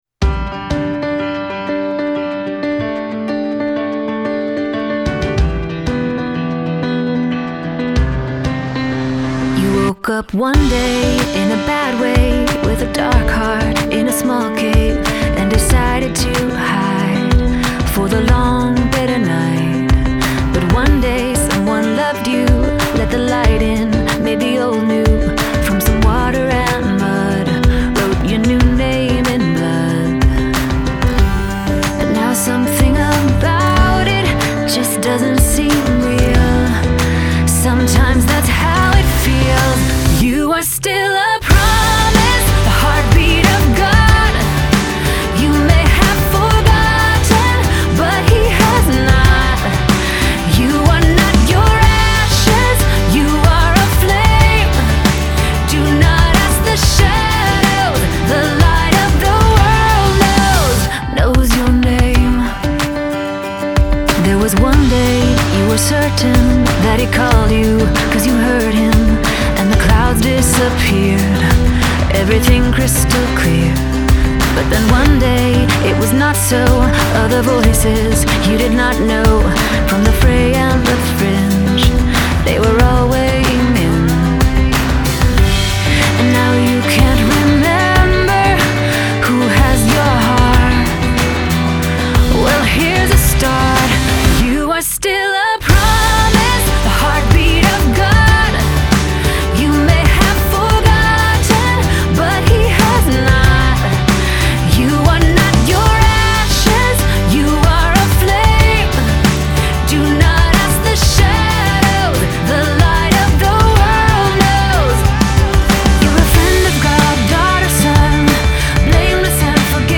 Genre: CCM, Pop, Pop Rock